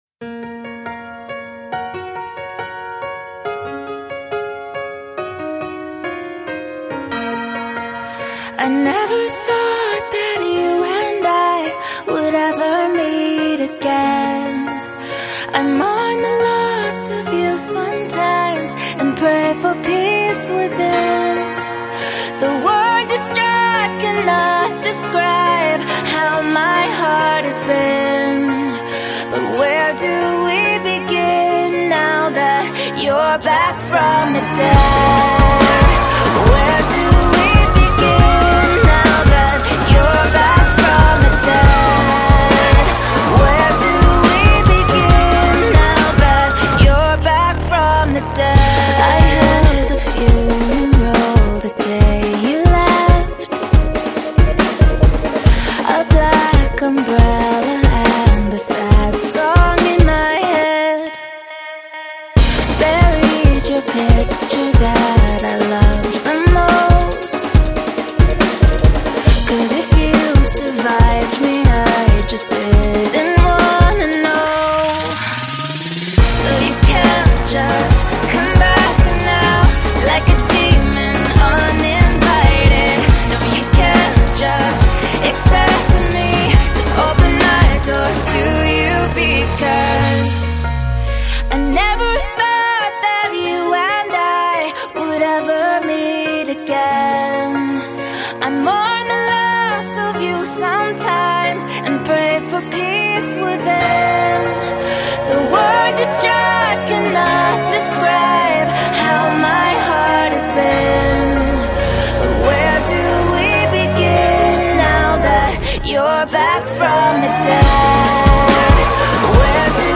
美国另类民谣摇滚女歌手